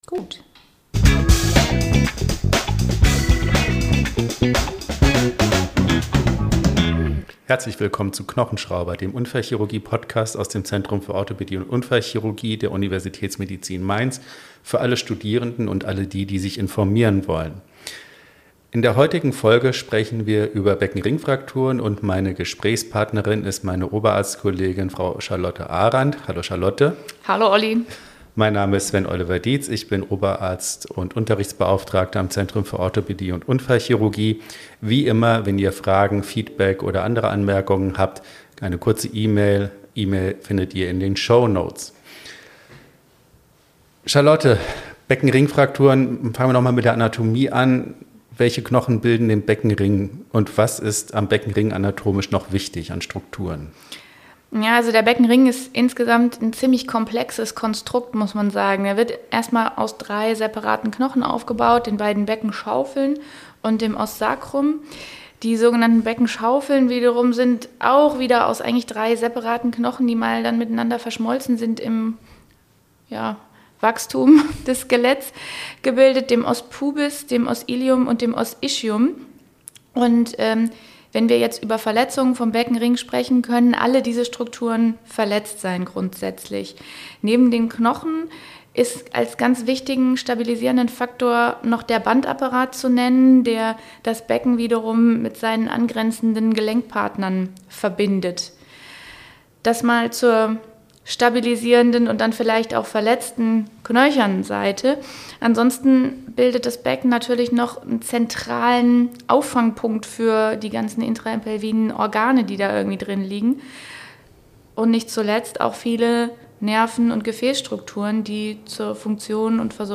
Interviewgästen